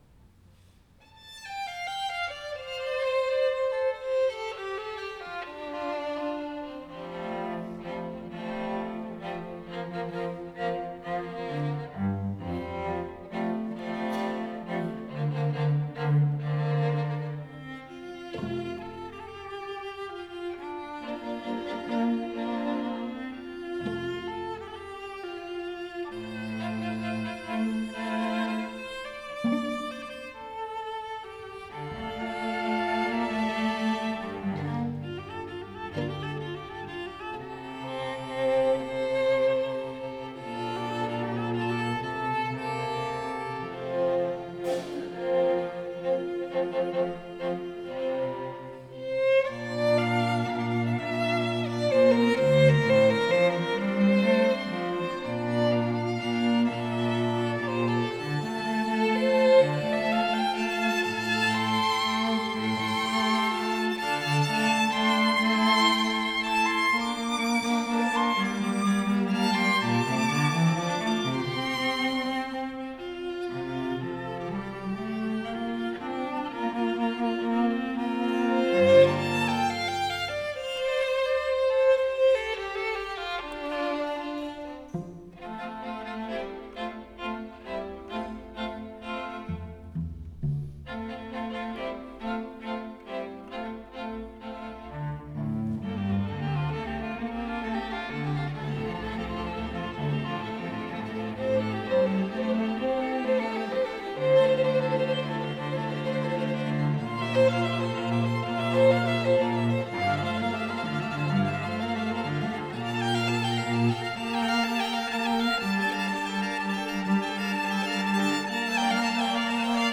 for String Quartet (2022)